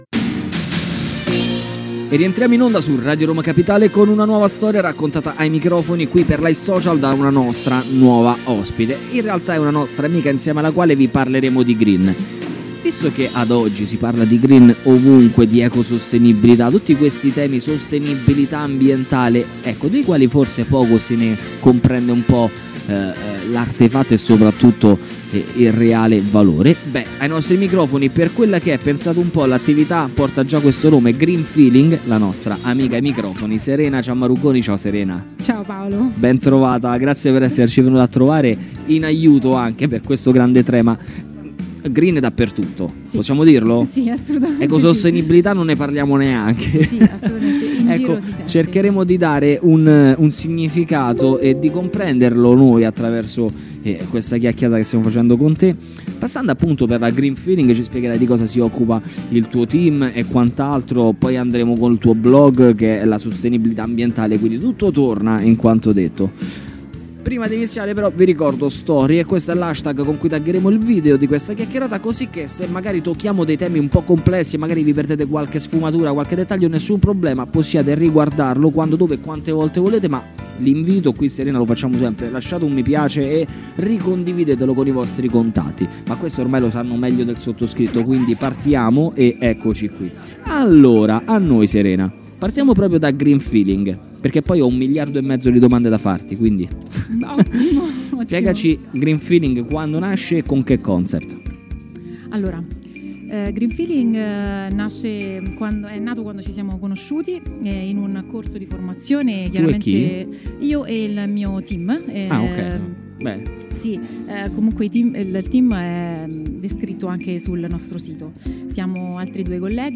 scelta da Radio Roma Capitale per il tema “green”